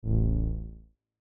ヴオン1
/ F｜演出・アニメ・心理 / F-80 ｜other 低音